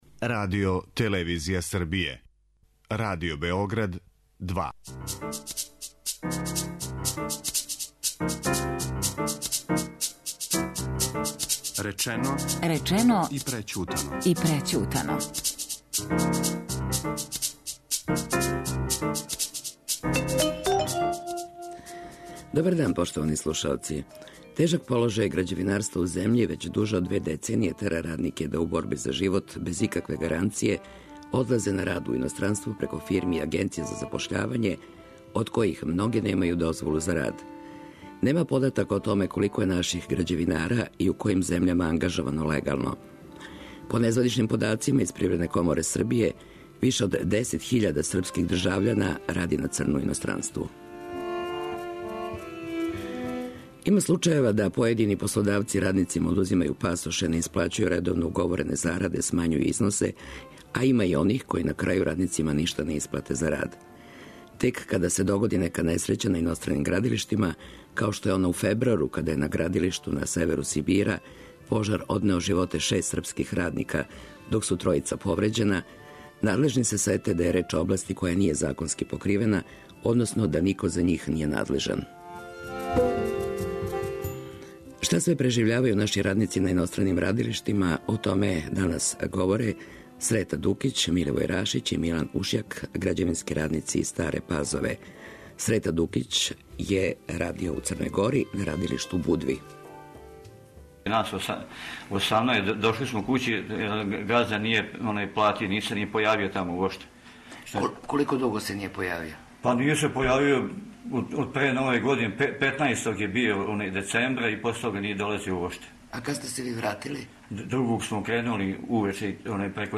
грађевински радници из Старе Пазове.